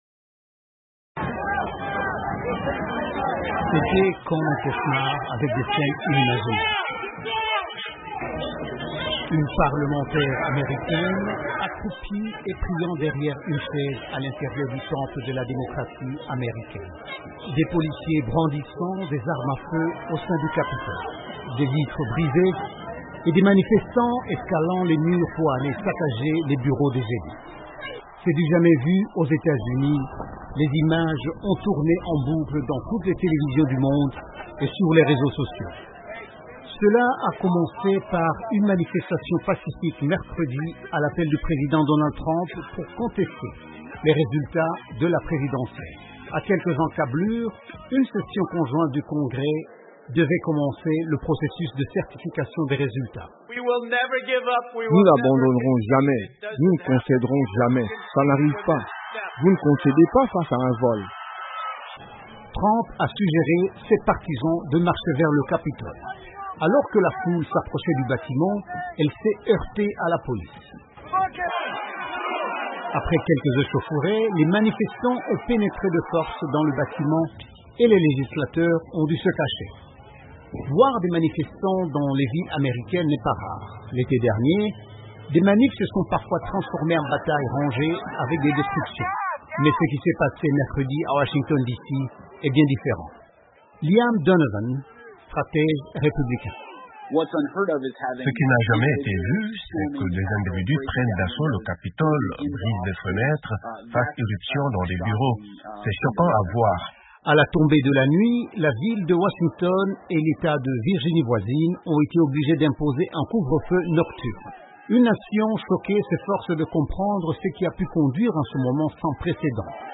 Des manifestants en colère ont envahi hier le Capitole, le parlement américain, grand symbole de la démocratie. Selon le chef de la police, 4 personnes ont été tuées dont une femme. Un reportage